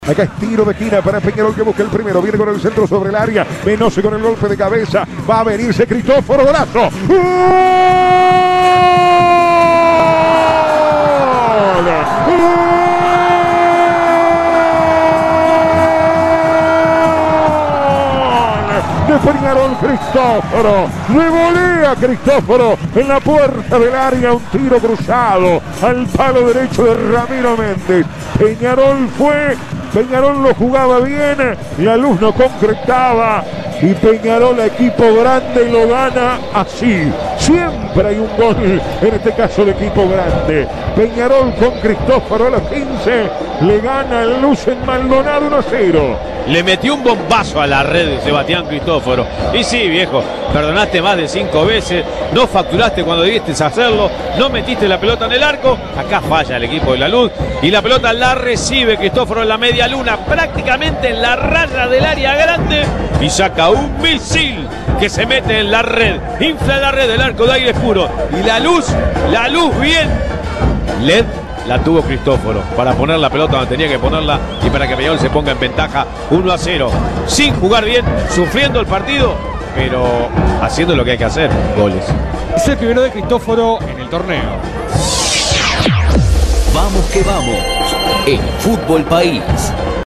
El partido de locos entre merengues y carboneros en ma voz del equipo de VQV